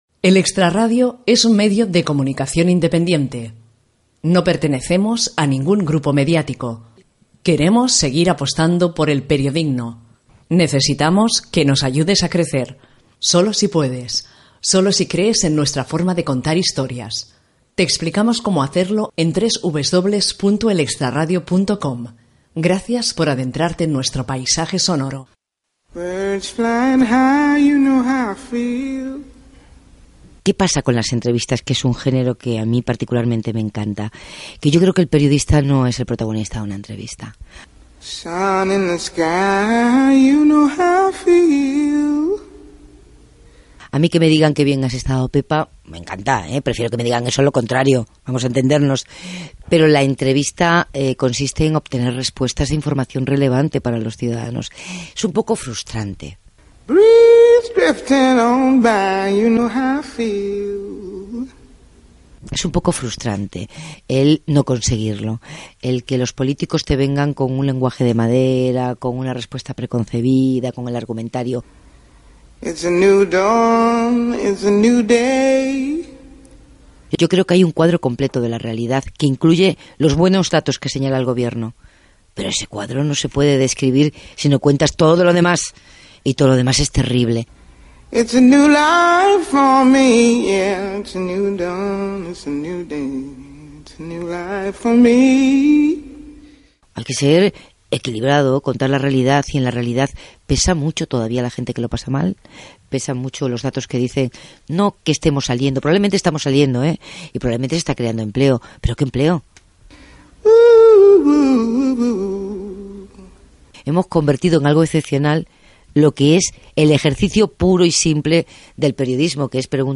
Declaració d'independència del mitjà de comunicació. Espai "Cuando éramos periodistas". Opinions de la periodista Pepa Bueno sobre el gènere de l'entrevista. Presentació i entrevista a Pepa Bueno